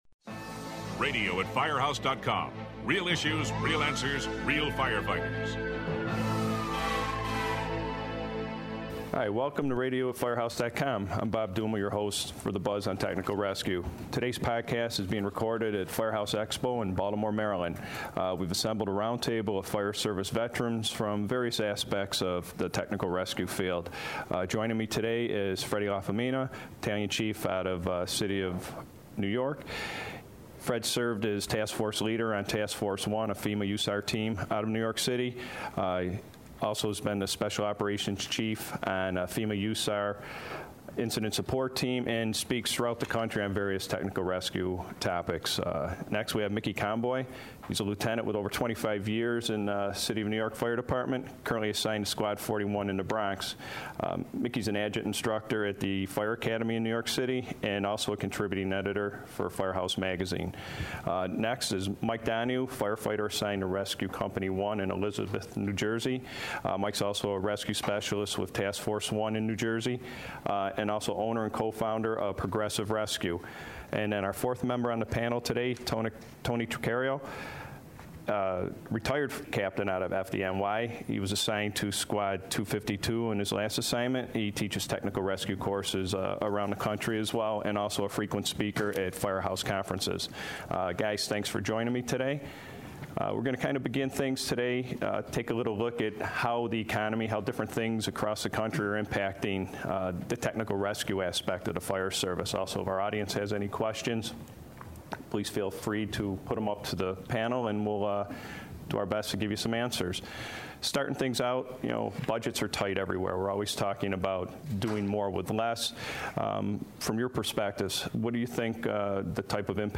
The group examines the impact of significant technical rescue events over the last year on the industry. They discuss how national media has helped bring attention to USAR and other teams and how it helps when it comes to justifying the teams.
Listen as these veteran panelists weigh in on training at the everyday level and advanced levels based needs.
This podcast was recorded at Firehouse Expo in July.